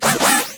PLA cries